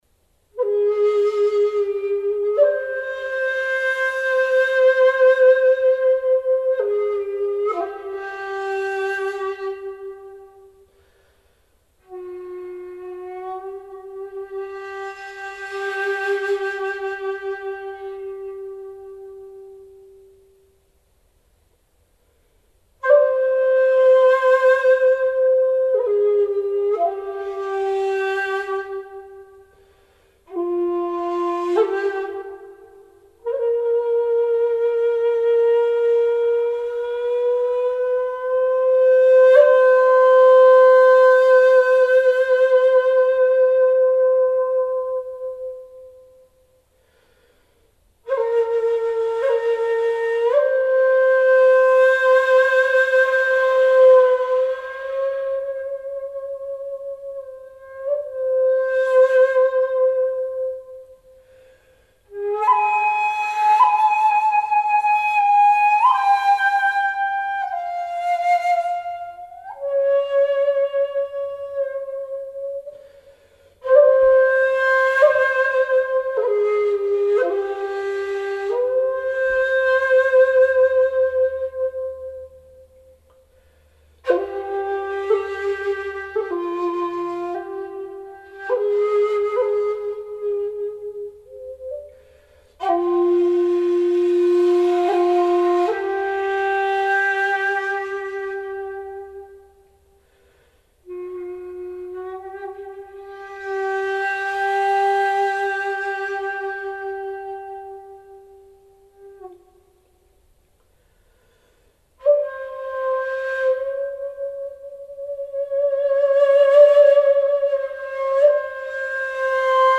Traditional honkyoku piece (excerpt)